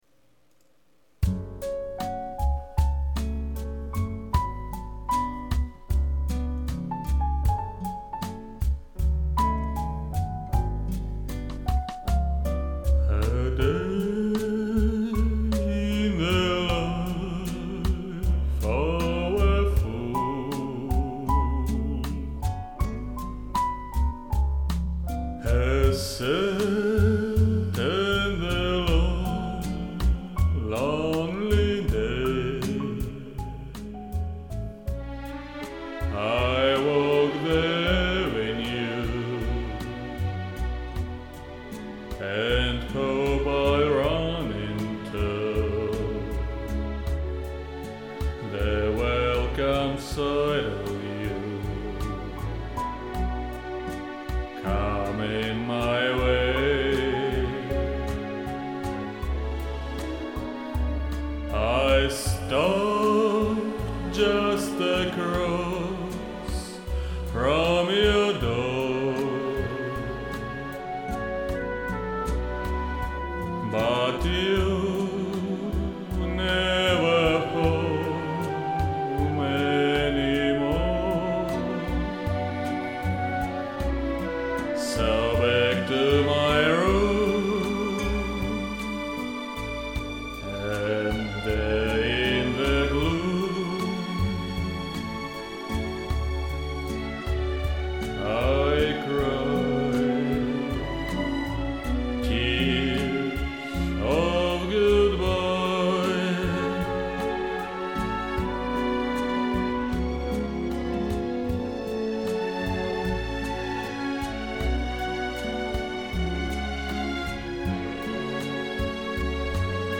красивая подача, профессиональный вокал....но все же мои 2:3
В коде очень красиво держите ноту!